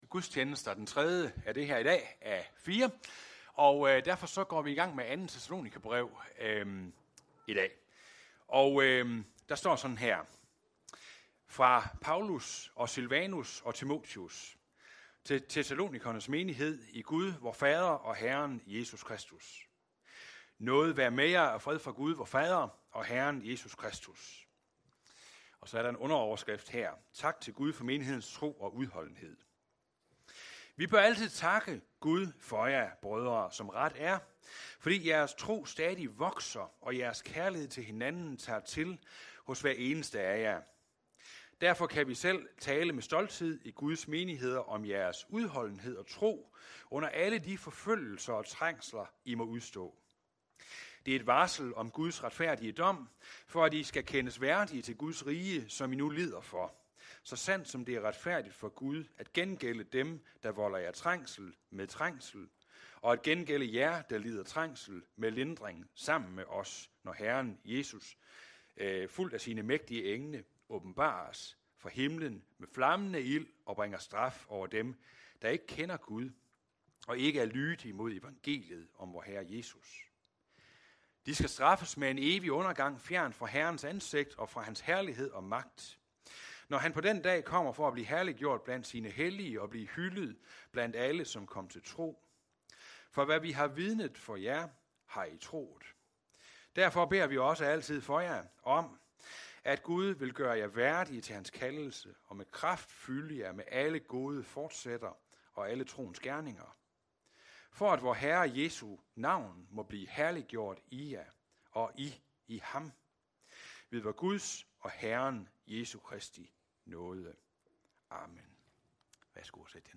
Undervisningsgudstjeneste (del 3 af 4) – Når troen prøves, lever håbet (2. Thess 1. 1-12)